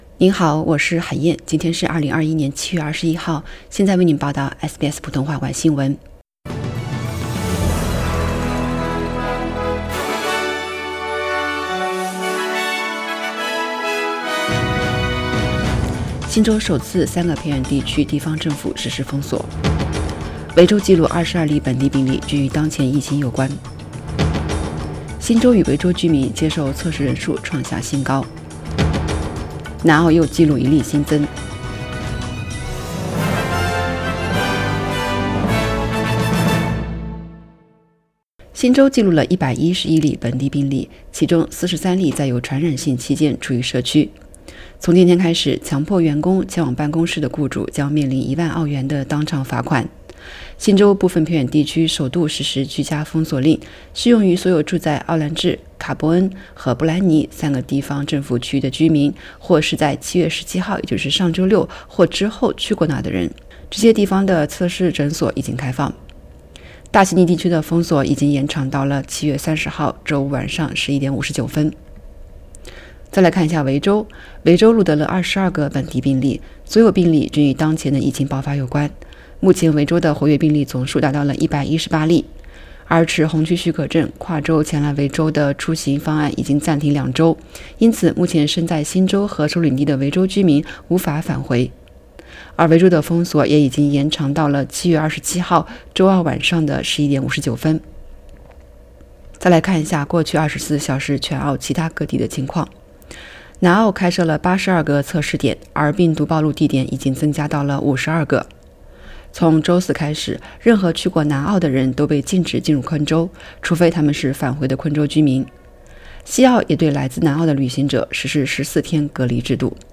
SBS晚新闻（7月21日）